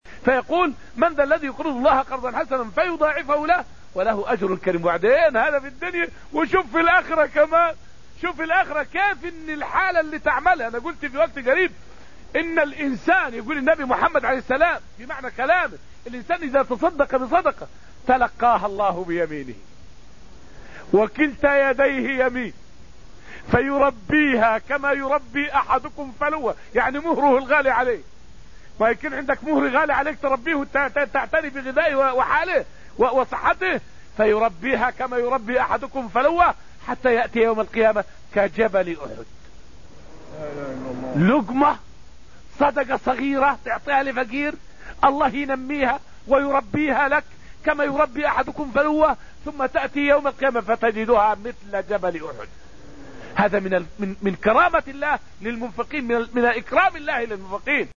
فائدة من الدرس الثاني عشر من دروس تفسير سورة الحديد والتي ألقيت في المسجد النبوي الشريف حول مضاعفة الثواب للمنفقين في الآخرة.